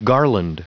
Prononciation du mot garland en anglais (fichier audio)
Prononciation du mot : garland